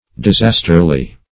disasterly - definition of disasterly - synonyms, pronunciation, spelling from Free Dictionary Search Result for " disasterly" : The Collaborative International Dictionary of English v.0.48: Disasterly \Dis*as"ter*ly\, adv.